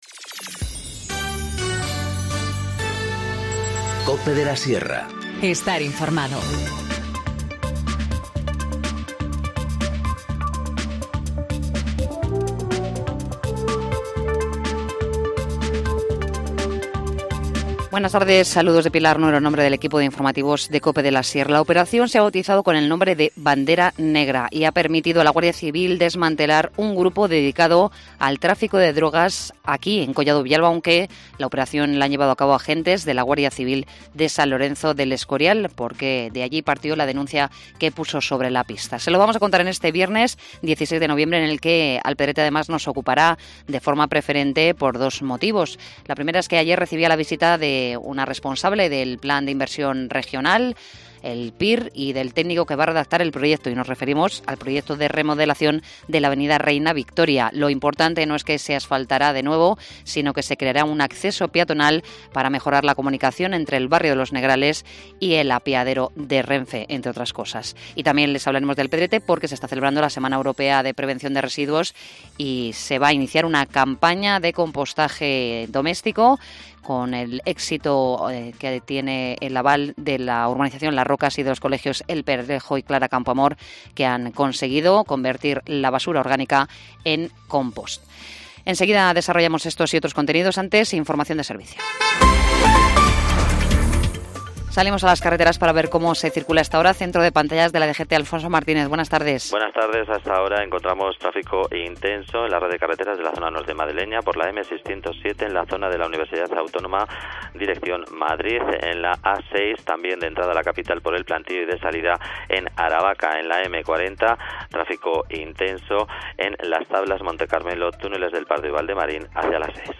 Informativo Mediodía 16 nov- 14:20h